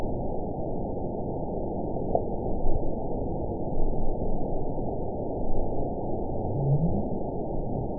event 921577 date 12/04/24 time 23:54:40 GMT (6 months, 2 weeks ago) score 9.60 location TSS-AB06 detected by nrw target species NRW annotations +NRW Spectrogram: Frequency (kHz) vs. Time (s) audio not available .wav